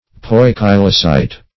Search Result for " poikilocyte" : The Collaborative International Dictionary of English v.0.48: Poikilocyte \Poi"ki*lo*cyte\ (poi"k[i^]*l[-o]*s[imac]t), n. [Gr. poiki`los diversified, changeable + ky`tos hollow vessel.]
poikilocyte.mp3